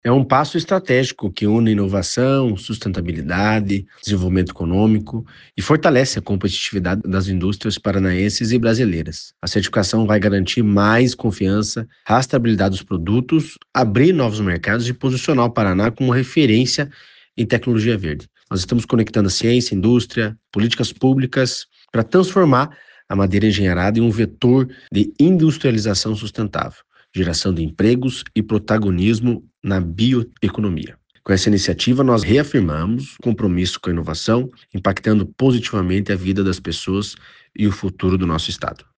Sonora do diretor-presidente do Tecpar, Eduardo Marafon, sobre o instituto liderar uma iniciativa inédita de certificação nacional da madeira engenheirada